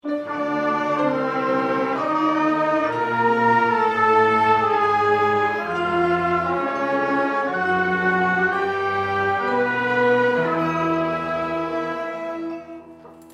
here are my recordings from the rehearsals